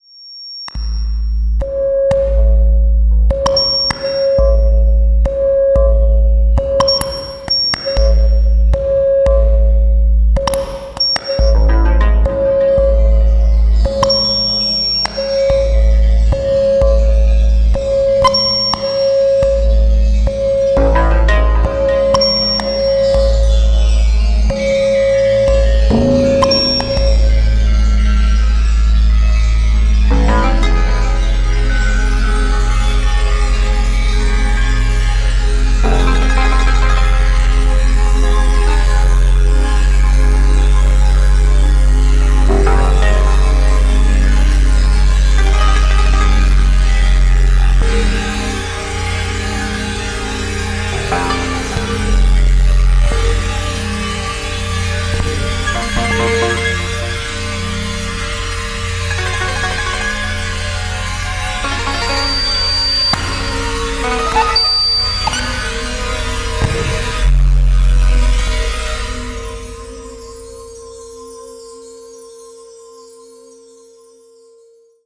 supplier of essential dance music
Electronix
Ambient